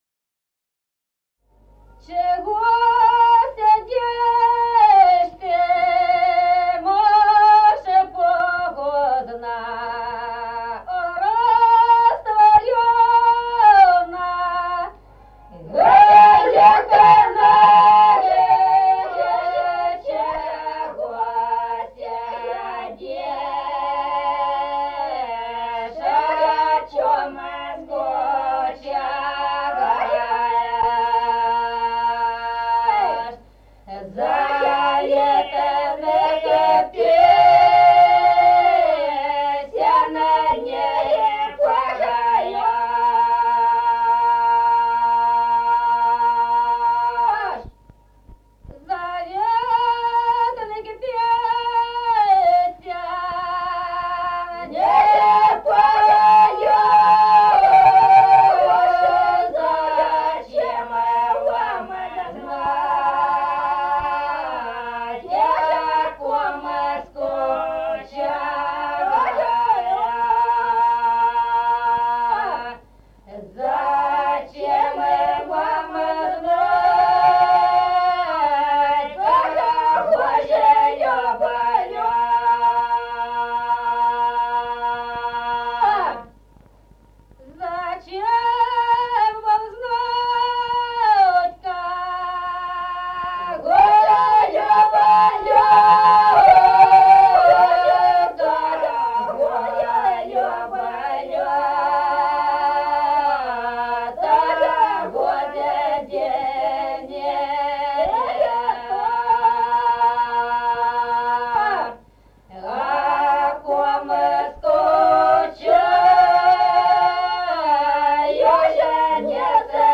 1953 г., с. Остроглядово.